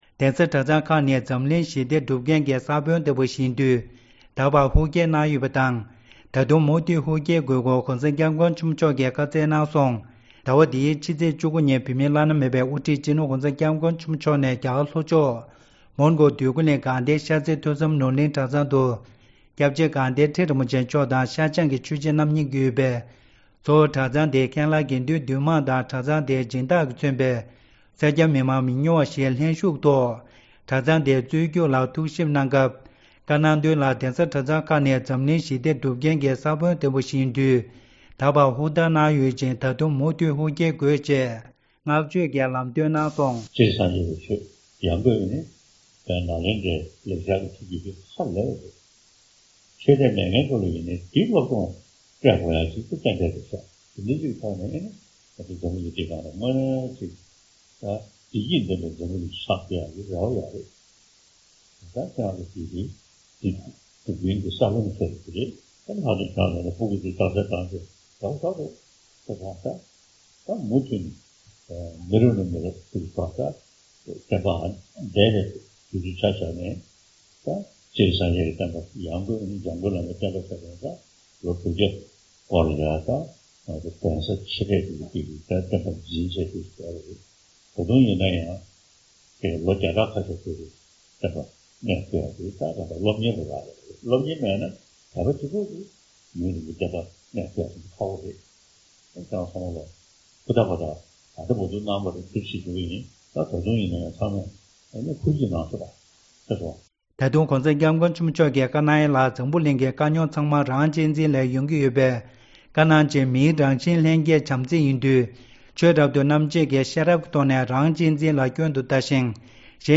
མིའི་ལྷན་སྐྱེས་ཀྱི་རང་བཞིན་དེ་བྱམས་དང་སྙིང་རྗེ་ཡིན། ༸གོང་ས་མཆོག ༸གོང་ས་མཆོག་ནས་དགའ་ལྡན་ཤར་རྩེ་གྲྭ་ཚང་དུ་བཀའ་སློབ་གནང་སྐབས།
སྒྲ་ལྡན་གསར་འགྱུར།